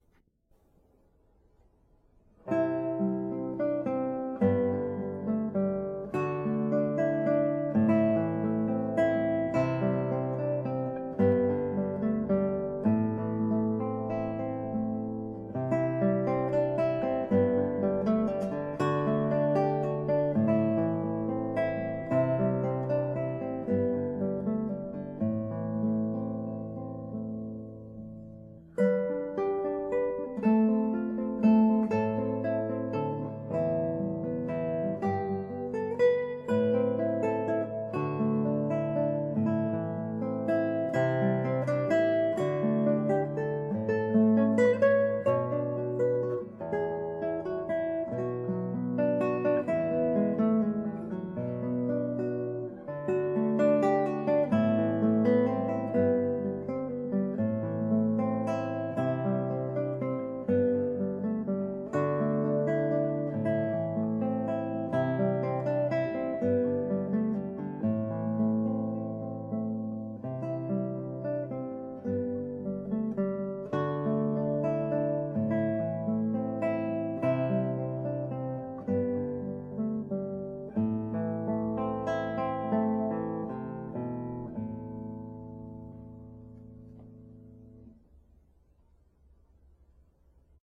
KOMPOSITIONEN FÜR  GITARRE  SOLO